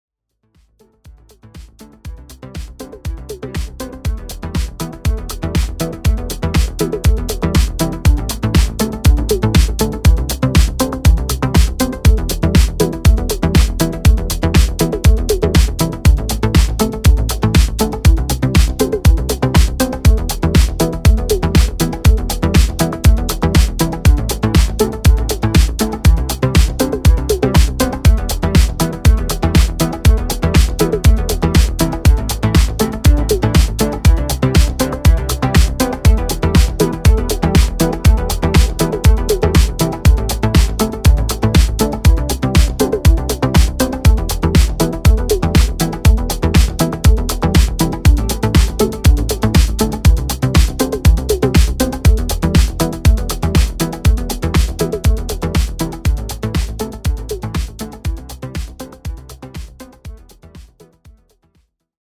Sonic stardust and kaleidoscopic grooves.